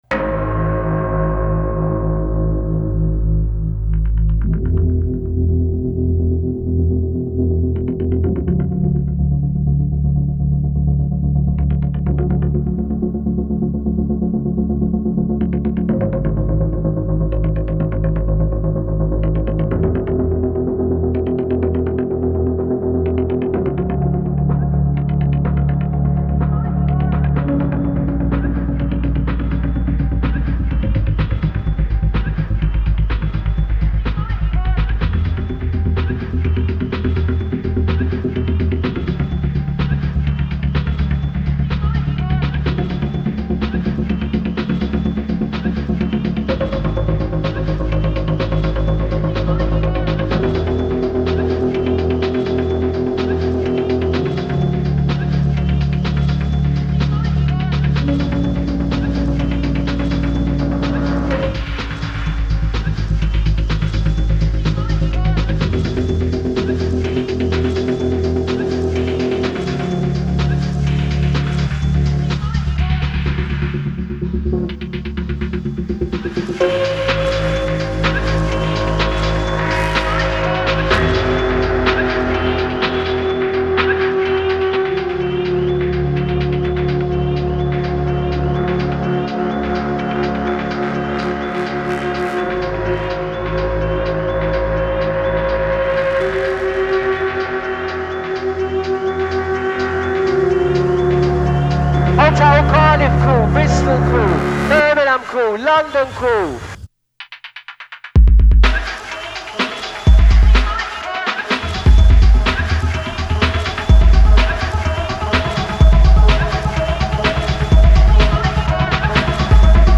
moody, echoing guitars and trip-hop influenced beats
EDM